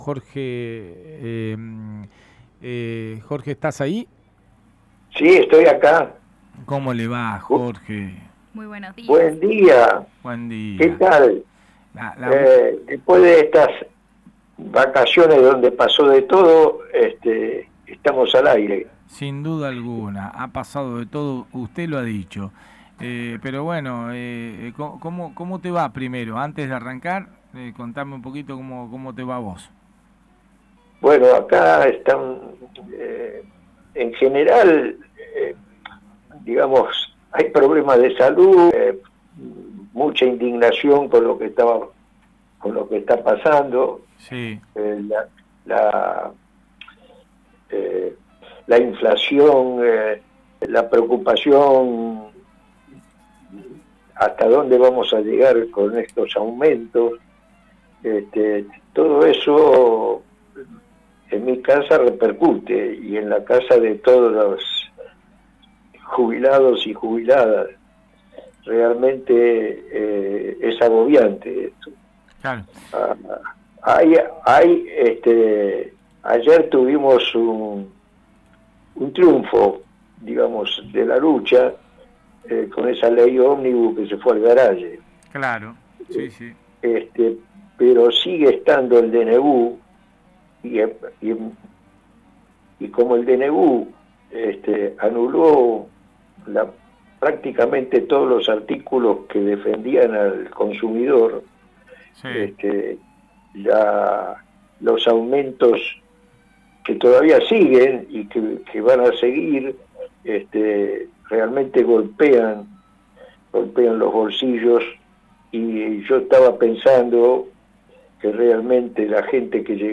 El entrevistado hizo mención sobre el comunicado de prensa que redacto la Mesa de Jubilados sobre la falta de acuerdo de la media sanción de la Ley Ómnibus.